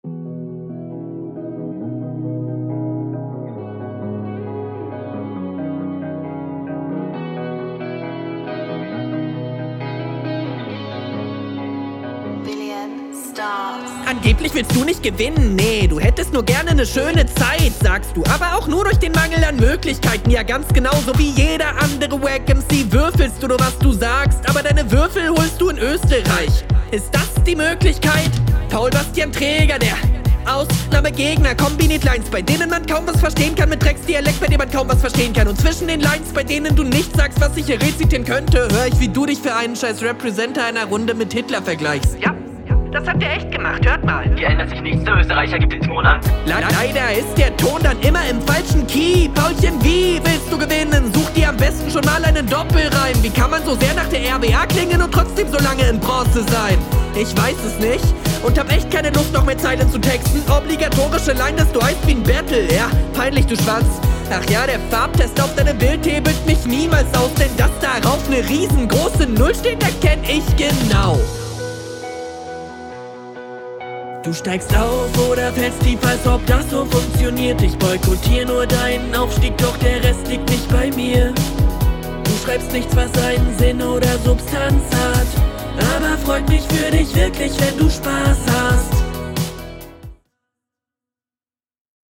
Ist textlich und flowlich super.